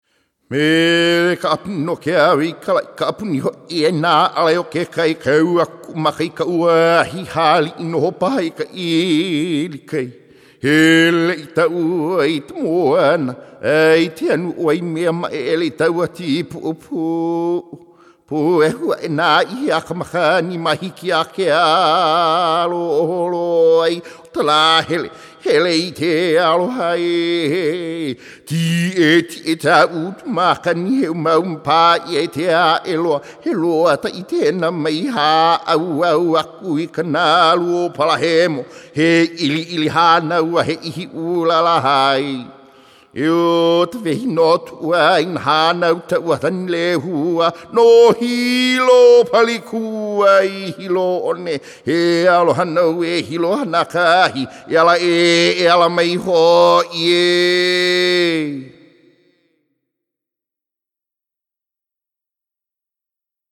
Hear a Hawaiian Chant